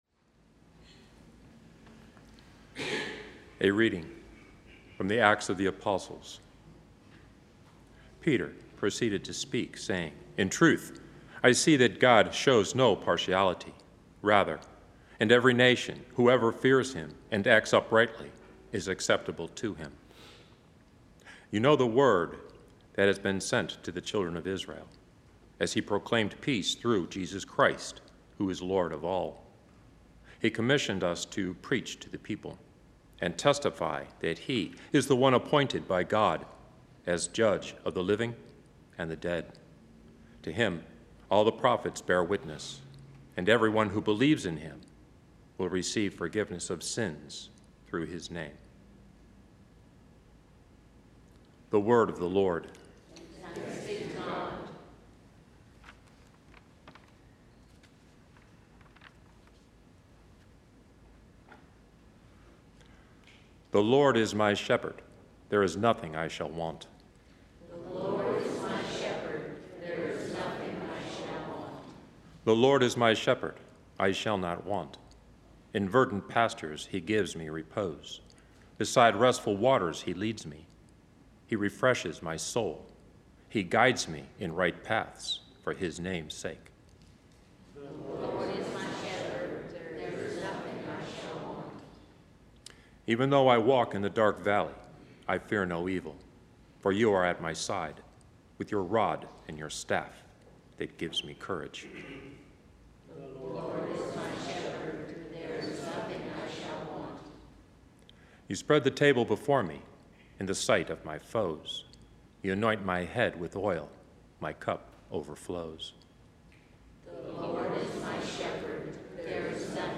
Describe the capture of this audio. From Our Lady of the Angels Chapel on the EWTN campus in Irondale, Alabama.